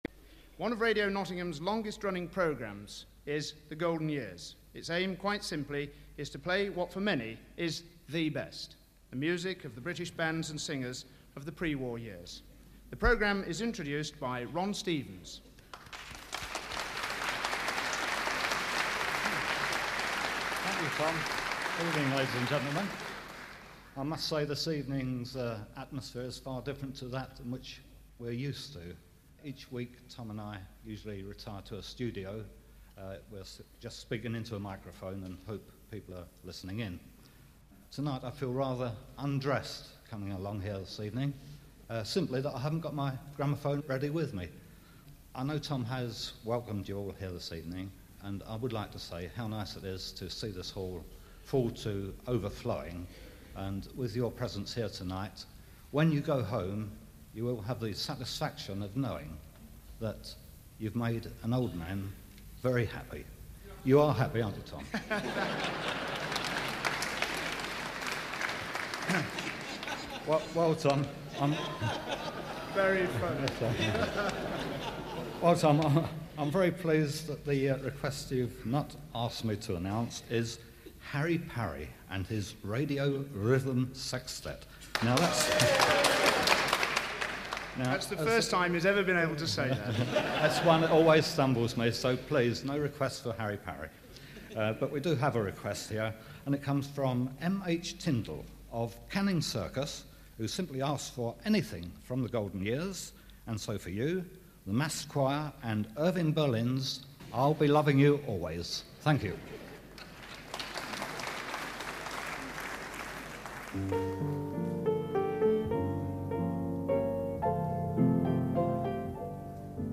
The Golden Years" every Sunday afternoon through much of the 70s and 80s on BBC Radio Nottingham